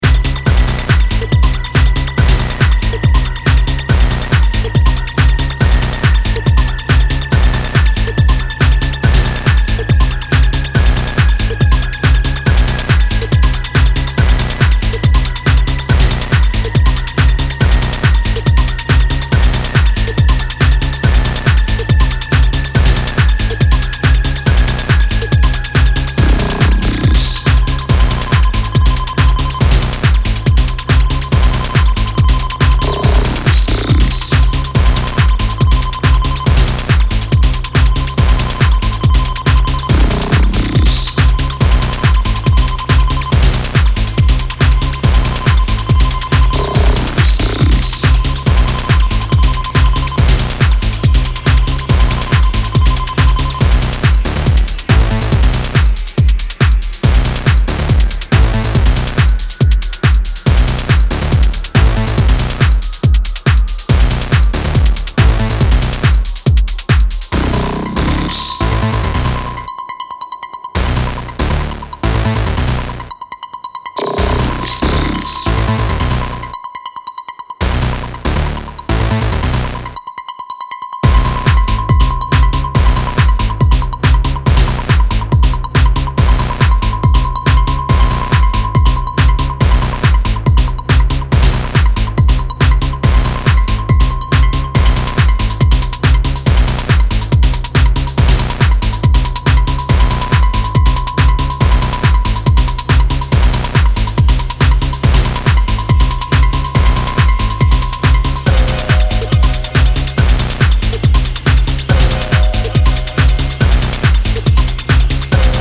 absolutely effective dance floor stormer